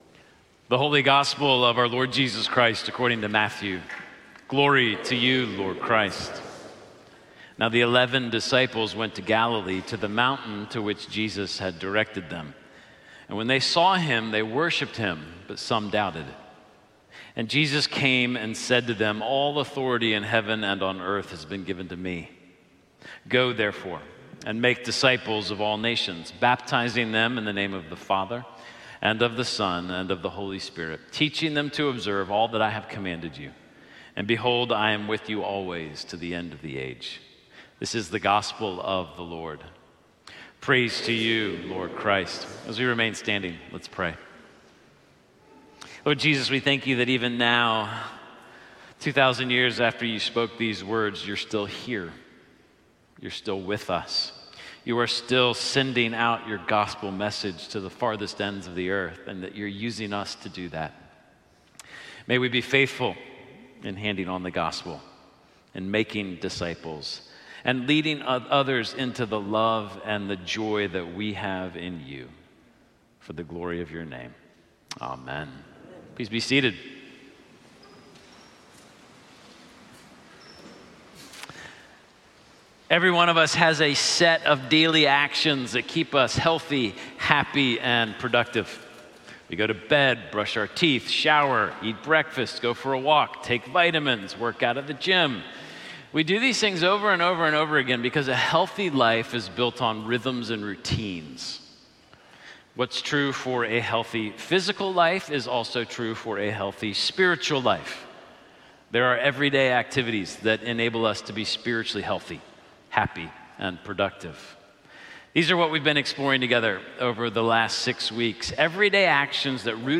Sermons - Holy Trinity Anglican Church
Sermon-April-6-2025.mp3